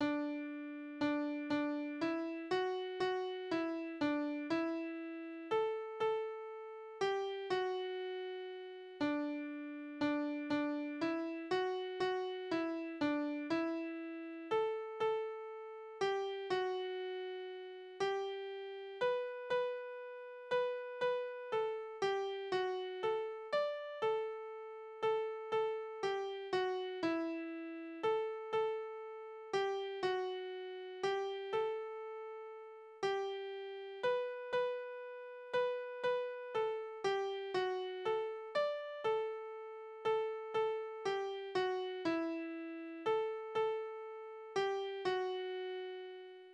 Soldatenlieder: Abschied an die treue Liebste
Tonart: D-Dur
Taktart: 3/4
Tonumfang: Oktave
Besetzung: vokal